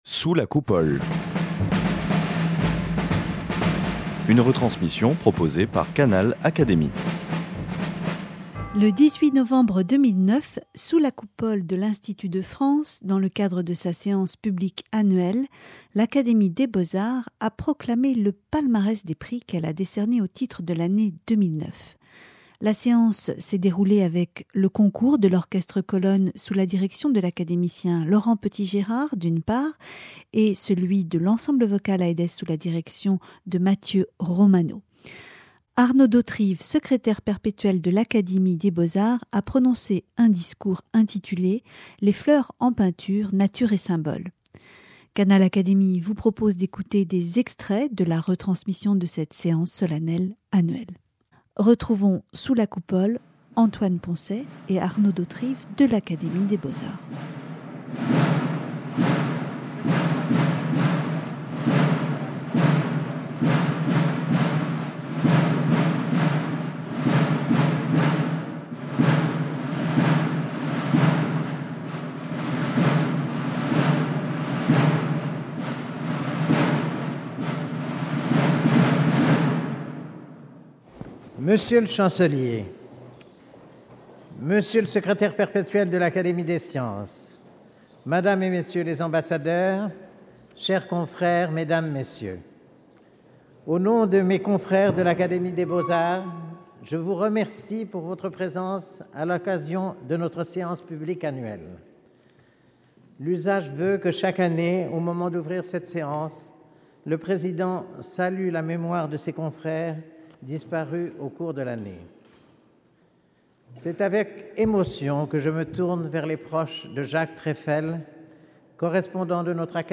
Le 18 novembre 2009, sous la Coupole de l’Institut de France, s’est déroulée la séance publique annuelle de l’Académie des beaux-arts.
Le Secrétaire perpétuel a prononcé, devant ses confrères, les lauréats et les invités, un discours sur Les fleurs dans la peinture.
Voici des extraits de cette séance.
Le sculpteur Antoine Poncet, président de l'Académie cette année a ouvert la séance et prononcé un discours en hommage aux disparus et fait observer une minute de silence. L'architecte Roger Taillibert, vice-président de l'Académie a proclamé le palmarès 2009.